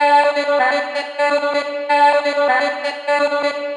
Female vocals Free sound effects and audio clips
• chopped vocals 109-127 female 1 (15) - Dm - 127.wav